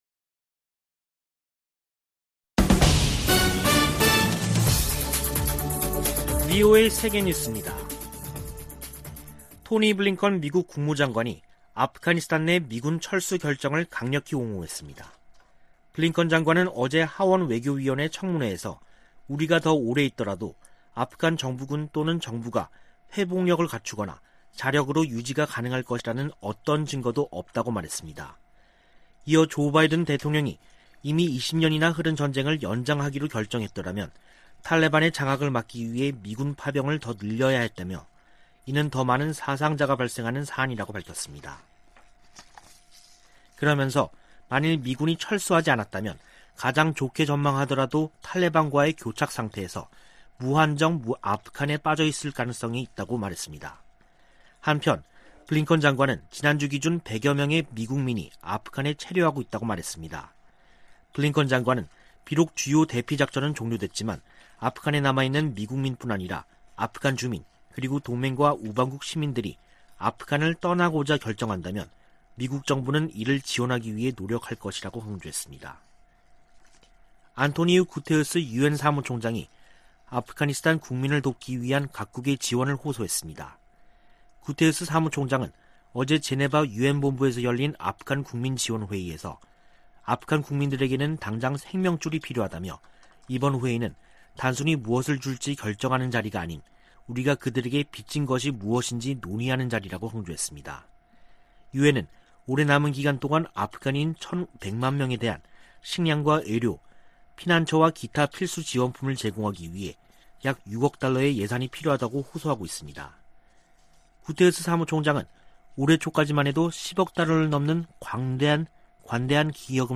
VOA 한국어 간판 뉴스 프로그램 '뉴스 투데이', 2021년 9월 14일 2부 방송입니다. 성 김 미국 대북특별대표는 미국은 북한에 적대적 의도가 없다며 북한이 대화 제의에 호응할 것을 거듭 촉구했습니다. 북한의 신형 장거리 순항미사일 시험발사는 도발이라기 보다는 무기체계 강화의 일환이라고 미국의 전문가들이 평가했습니다. 북한의 미사일 발사는 주민들의 식량을 뺏는 행위라고 국제인권단체가 비판했습니다.